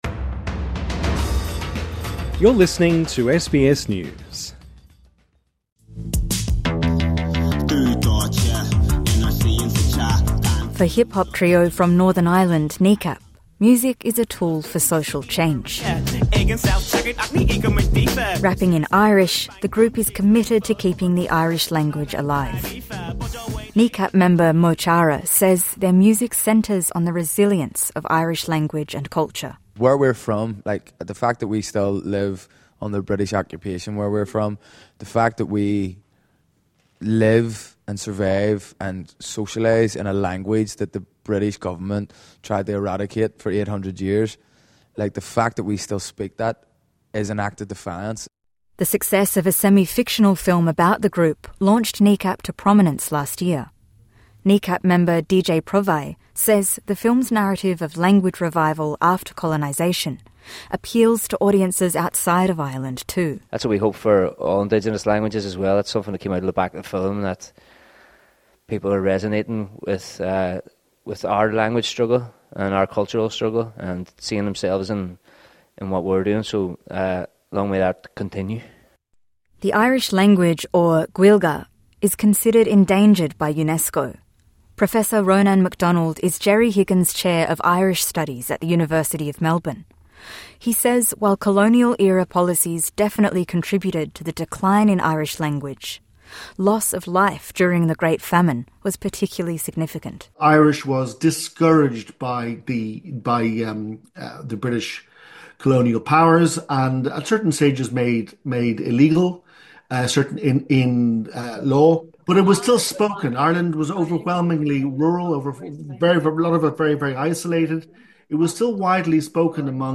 SBS News In Depth